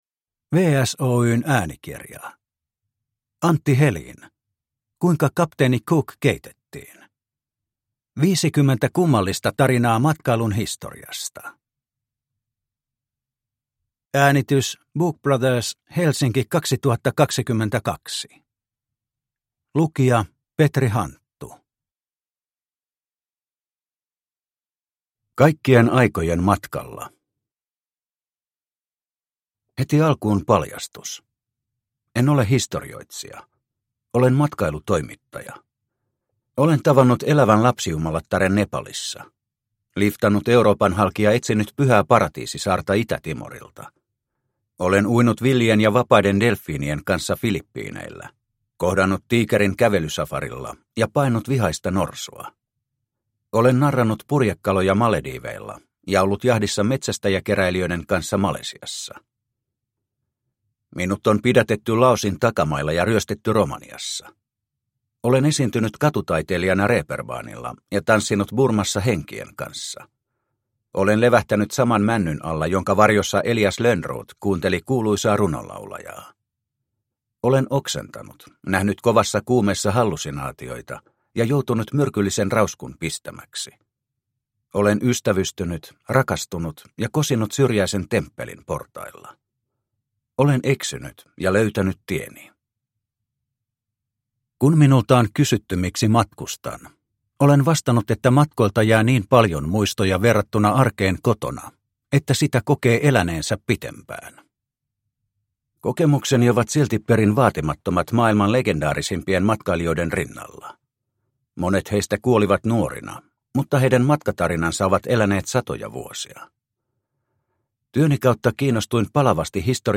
Kuinka kapteeni Cook keitettiin – Ljudbok – Laddas ner